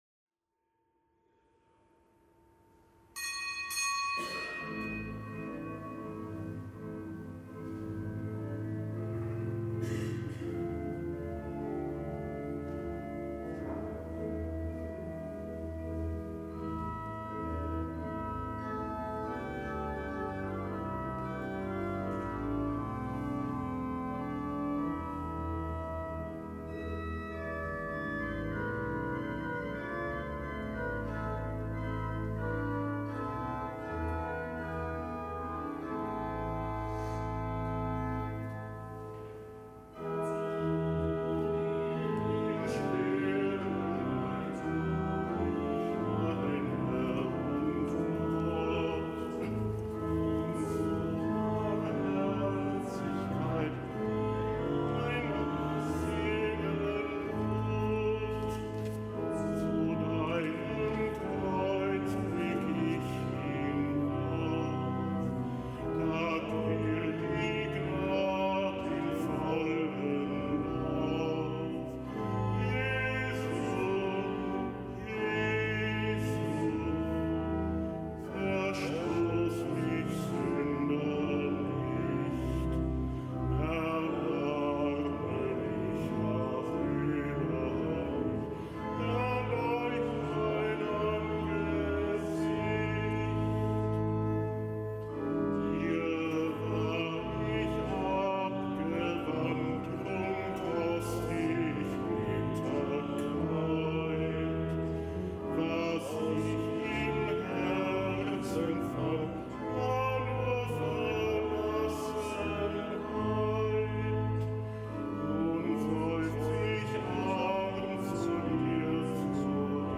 Kapitelsmesse am Mittwoch der vierten Fastenwoche
Kapitelsmesse aus dem Kölner Dom am Mittwoch der vierten Fastenwoche, nichtgebotener Gedenktag des Heiligen Franz von Páola, Einsiedler, Ordensgründer.